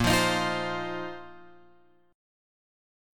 A# Suspended 2nd Suspended 4th